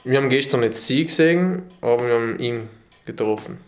- dialetto tirolese di Salorno nella Bassa Atesina (2016);